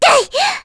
Luna-Vox_Attack4.wav